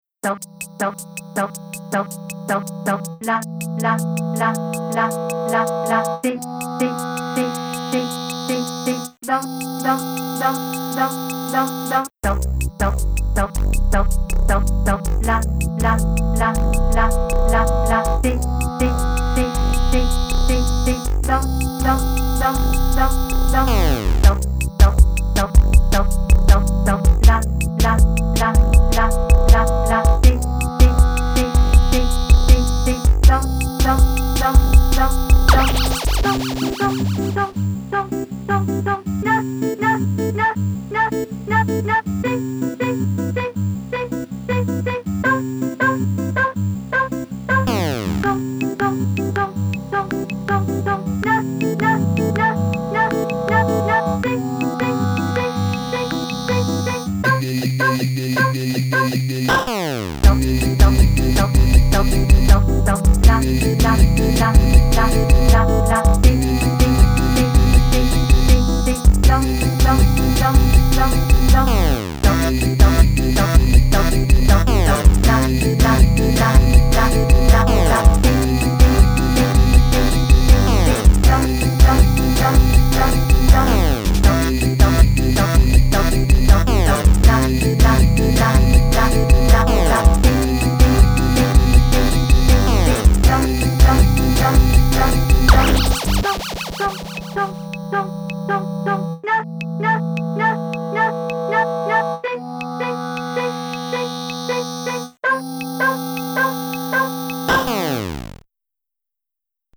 Catchy 8-bit electronics with quirky voice textures.